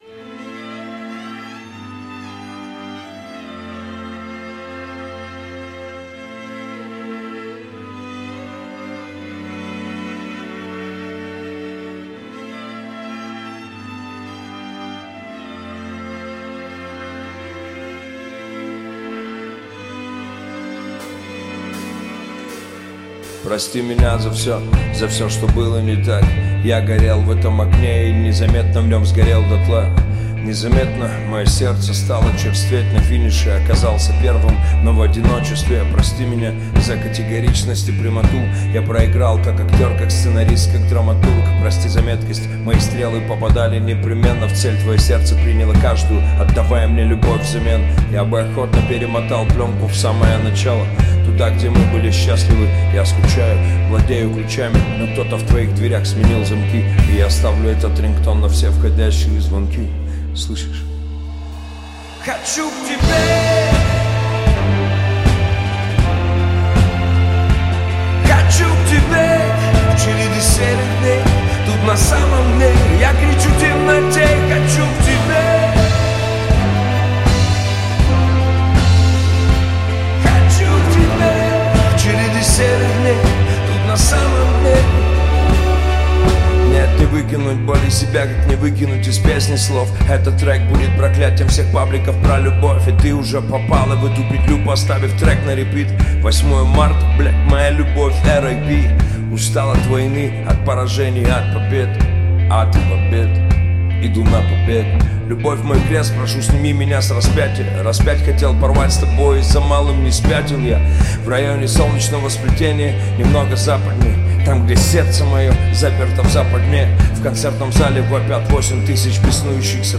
Русский рэп
Жанр: Русский рэп / Хип-хоп